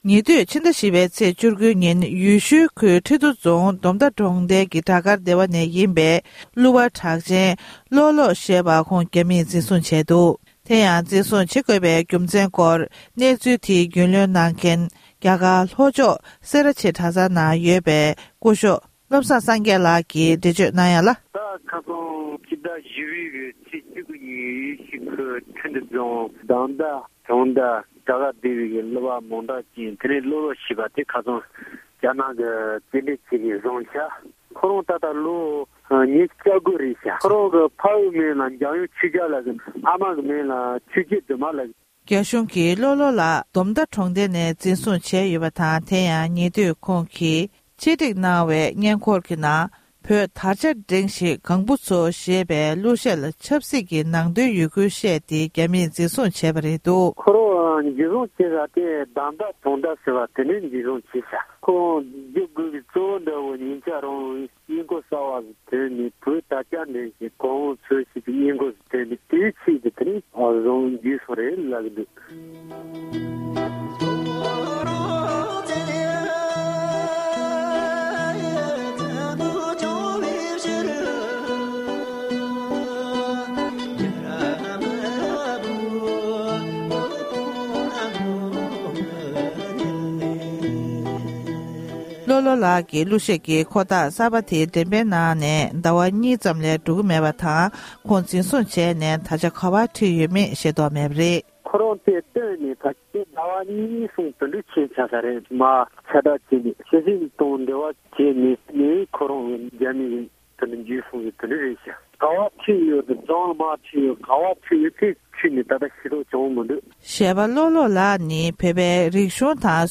སྒྲ་ལྡན་གསར་འགྱུར།
གནས་འདྲི